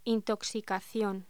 Locución: Intoxicación
voz